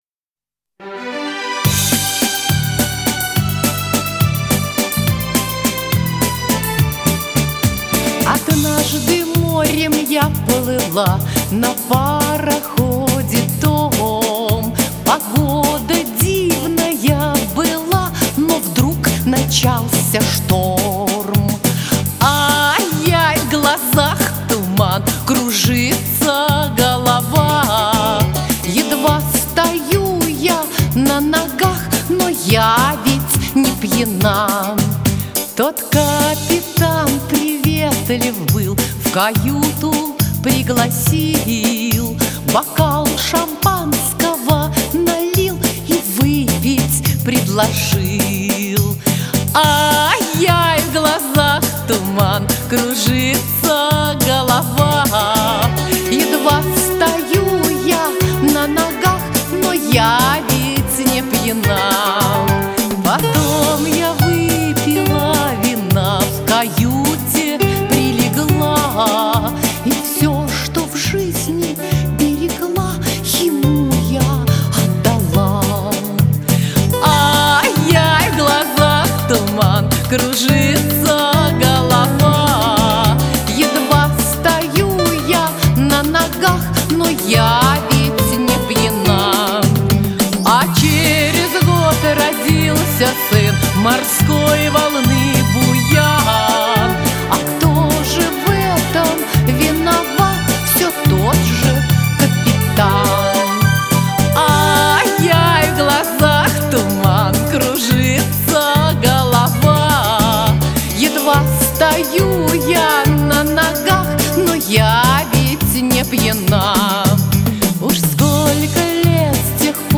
Романсы, городской фольклор и народные песни.